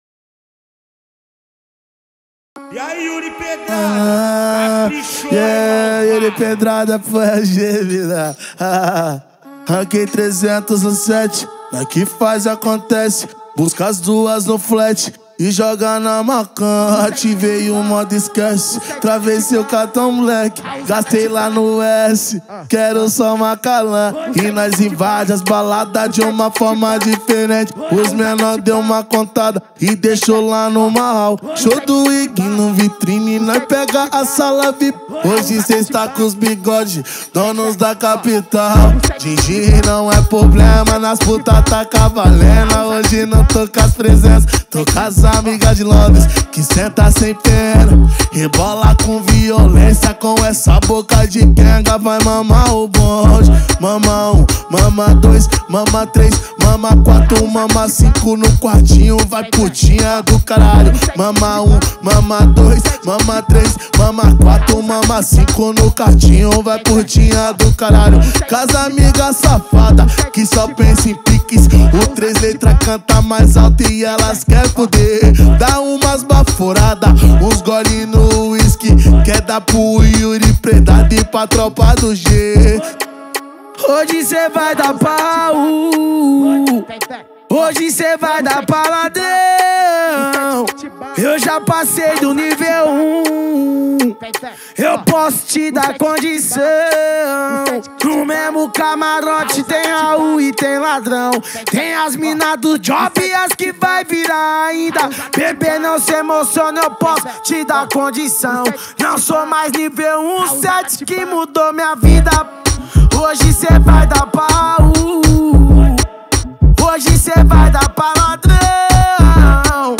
2024-10-25 22:24:29 Gênero: MPB Views